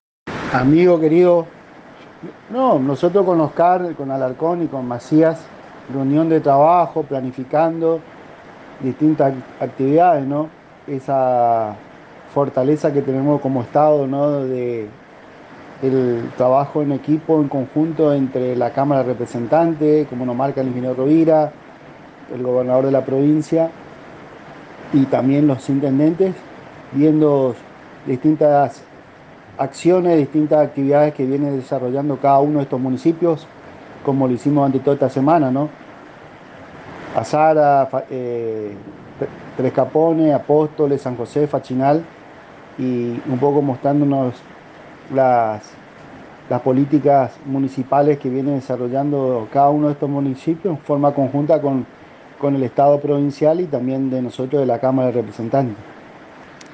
En la Mañana de este miércoles el Diputado Provincial Martin Cesino dialogó en una entrevista exclusiva para la Agencia de Noticias Guacurarí y comentó sobre las visitas que están realizando junto al ministro de Salud Pública de Misiones, Dr. Oscar Alarcón y el presidente de la Dirección Provincial de Vialidad Sebastián Macías a los municipios de la Zona Sur de la Provincia.